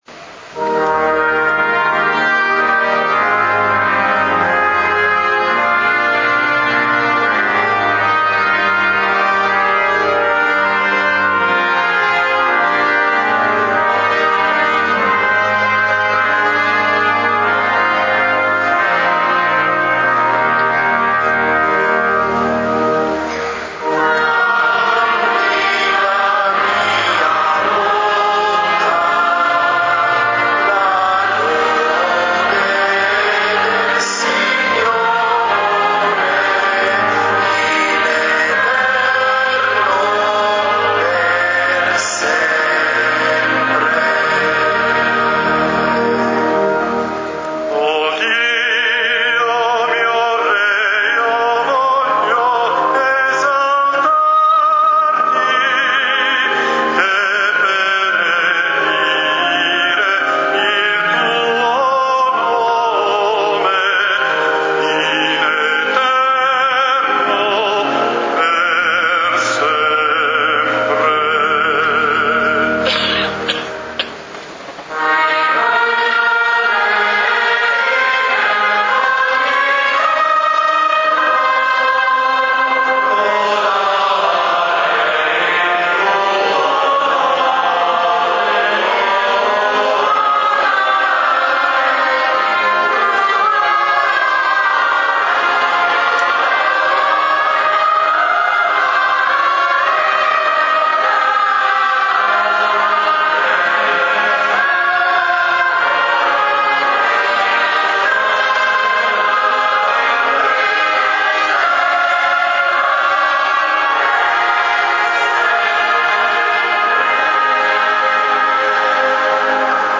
Gallery >> Audio >> Audio2013 >> Ingresso Arcivescovo Pennisi >> mp3-Canti la mia bocca